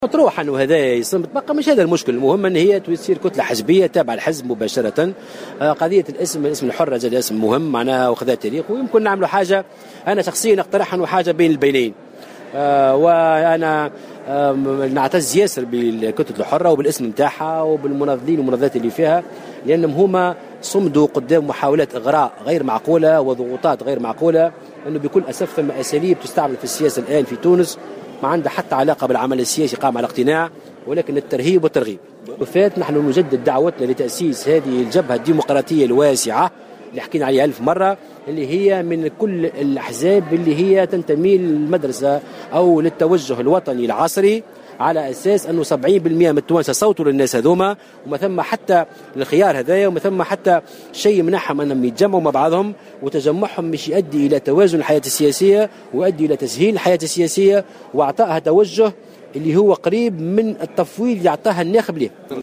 وأضاف مرزوق في تصريح لمراسلة "الجوهرة أف أم" على هامش اختتام أشغال المؤتمر التأسيسي لحركة مشروع تونس المنعقد بالحمامات " هناك للأسف أساليب تستعمل في السياسية للترهيب والترغيب."